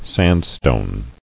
[sand·stone]